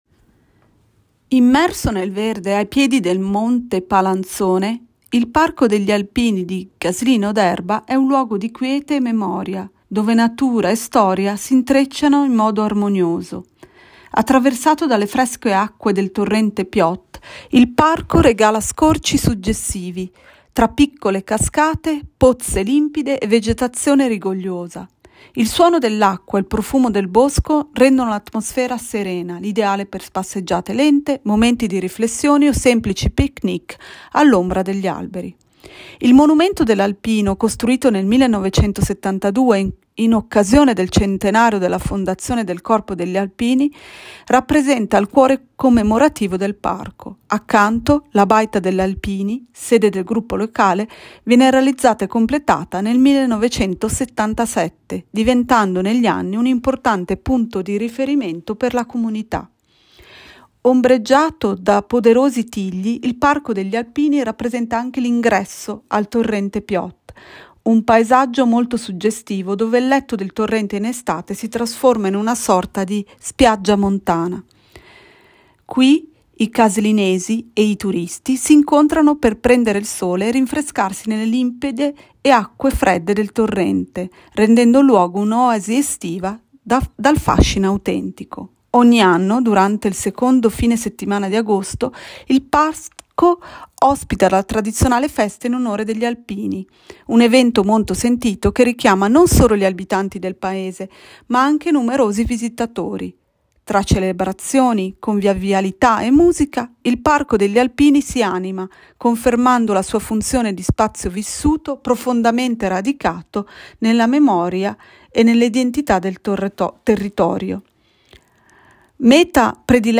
Audioguida